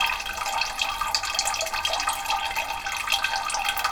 water_running_dripping_wee_loop_02.wav